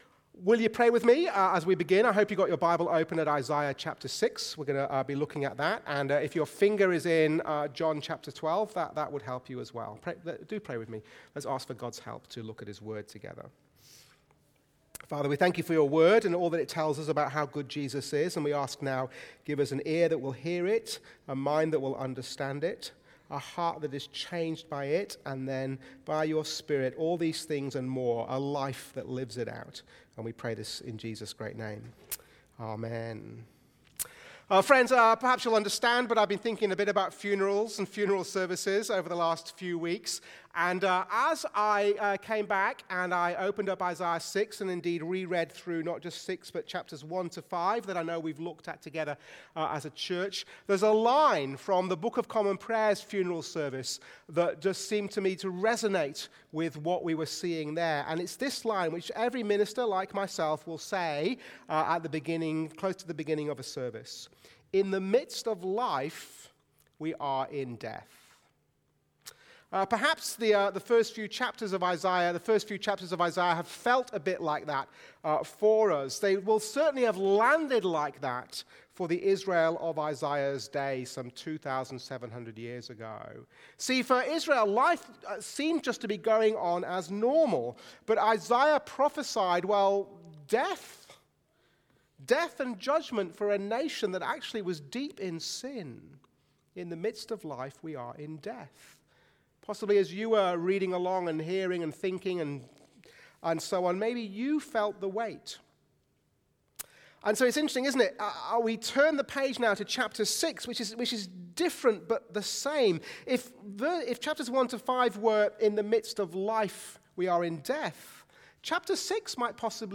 Sunday sermon
Watch the full service on YouTube or listen to the sermon audio only.